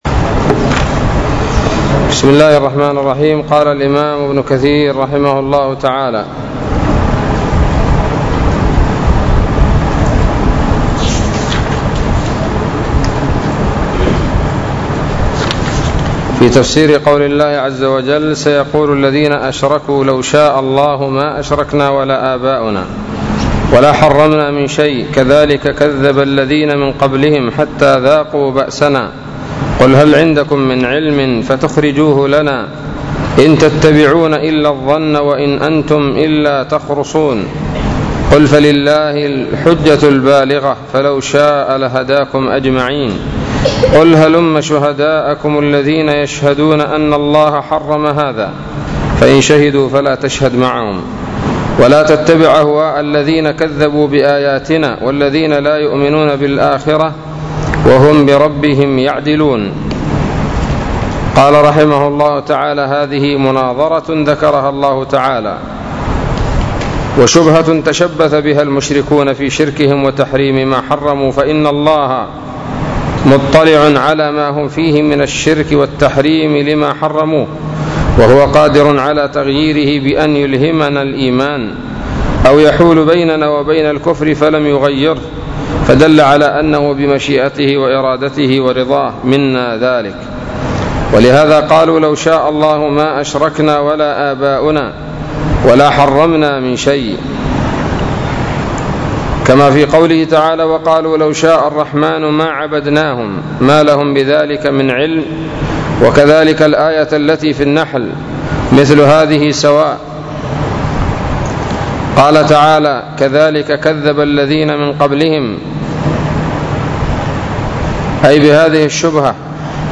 الدرس الثالث والستون من سورة الأنعام من تفسير ابن كثير رحمه الله تعالى